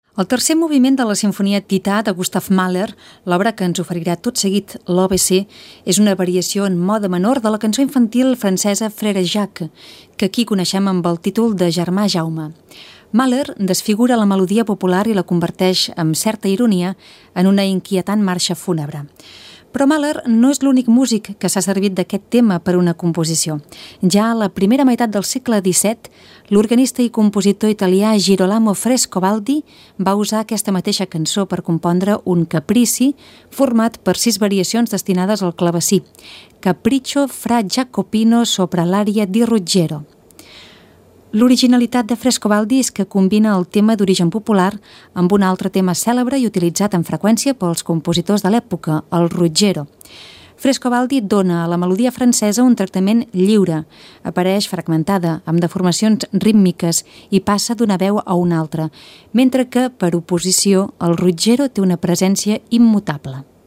Musical
Presentador/a